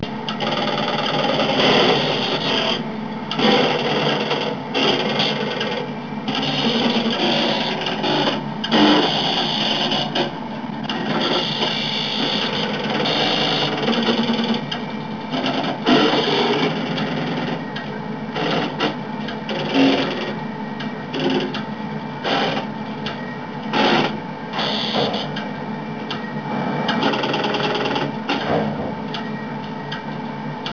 Sound of a 400 line PABX3 332k (30 secs)
pabx3.wav